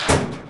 ammocrate_close.wav